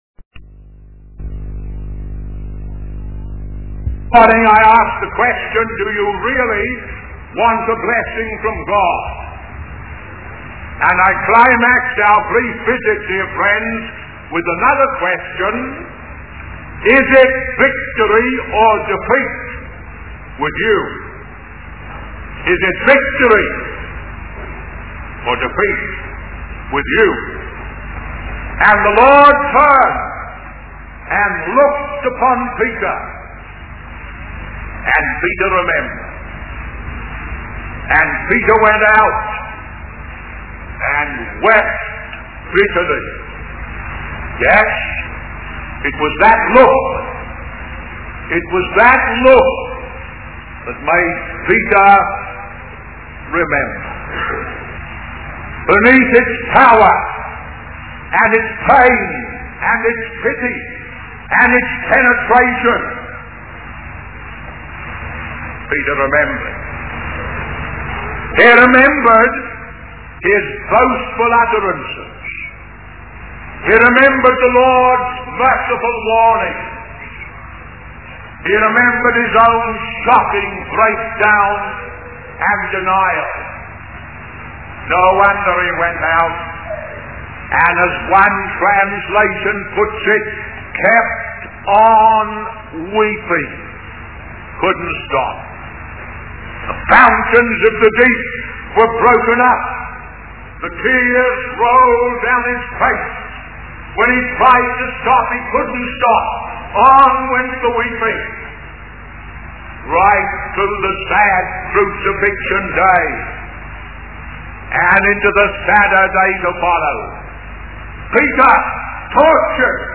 The sermon serves as a call to action for believers to seek victory through reliance on God's mercy and strength.